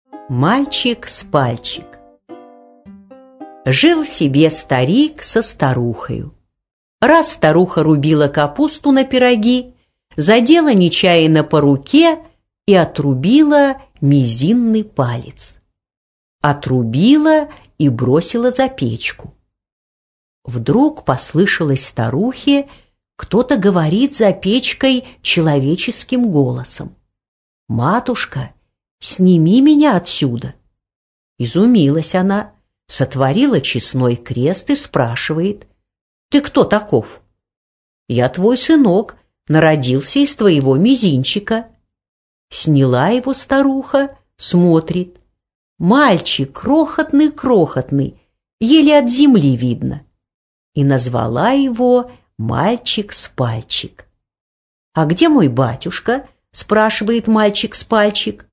Аудиокнига Мальчик-с-пальчик | Библиотека аудиокниг